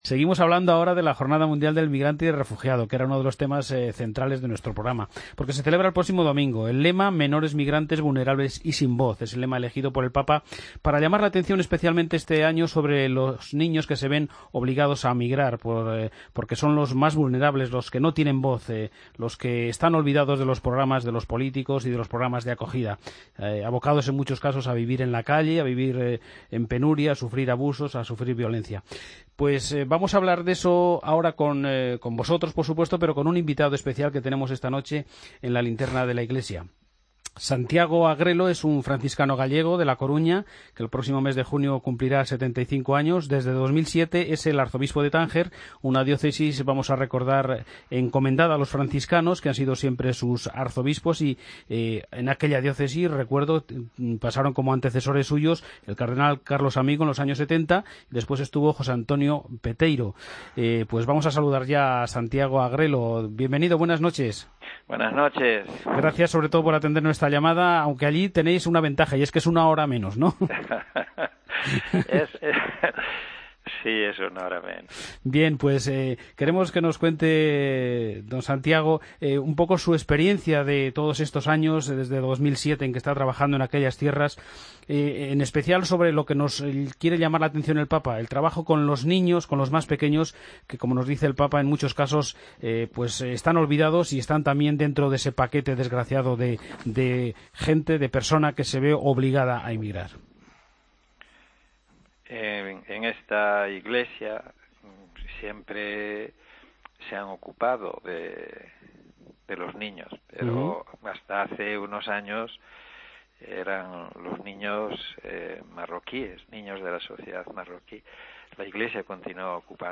AUDIO: Monseñor Santiago Agrelo Martínez, franciscano gallego y arzobispo de Tánger, en 'La Linterna de la Iglesia'